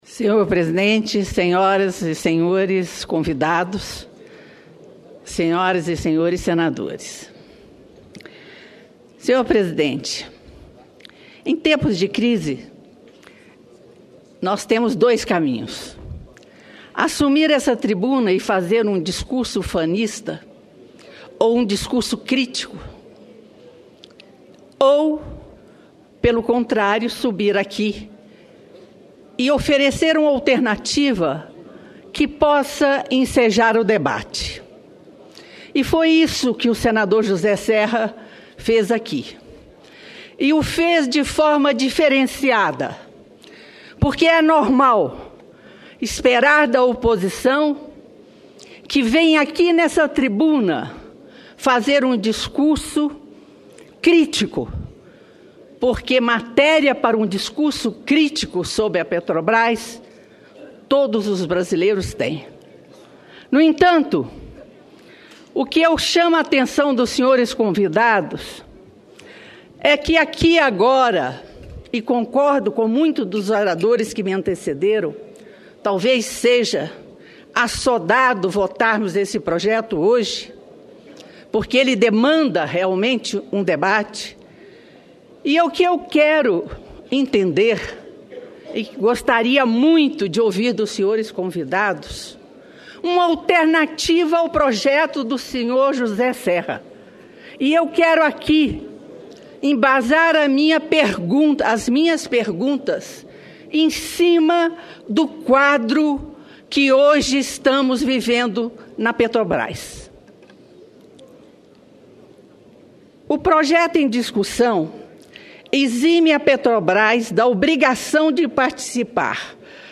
Pronunciamento da senadora Lúcia Vânia